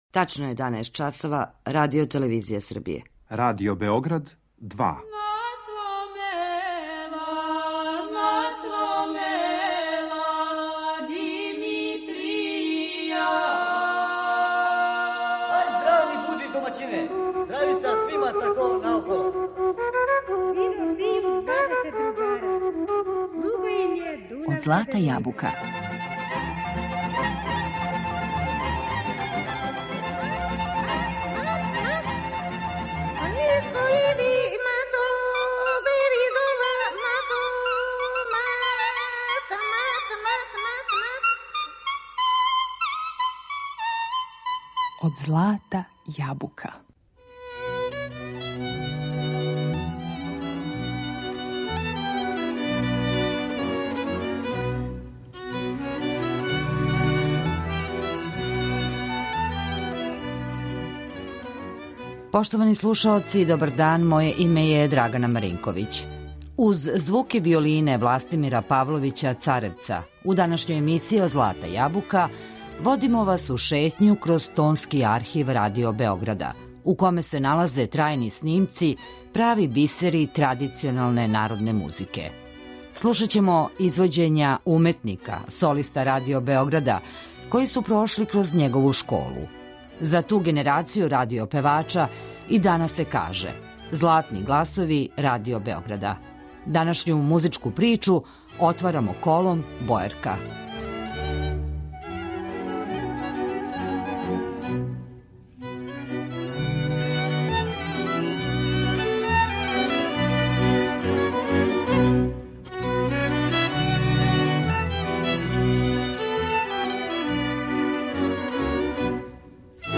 У данашњој емисији Од злата јабука водимо вас у шетњу кроз Тонски архив Радио Београда, где се налазе трајни снимци ‒ прави бисери традиционалне народне музике.